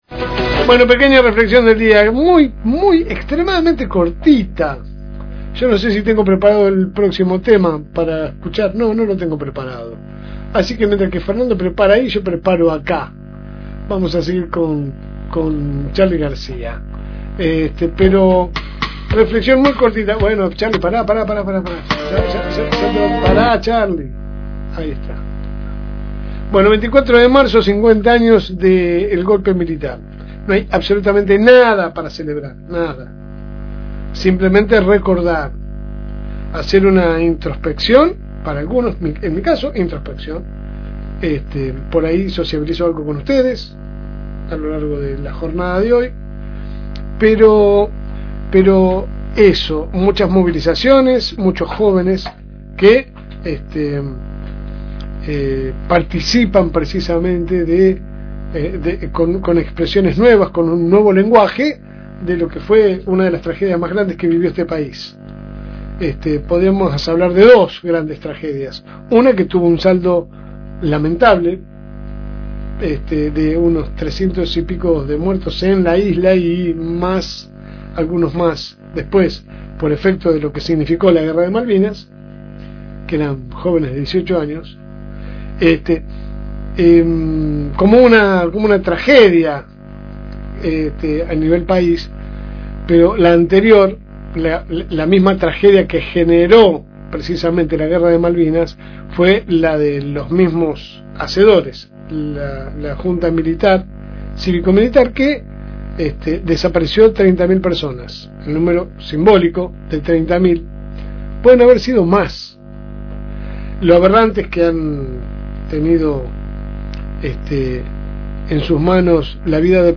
Editorial LSM (3)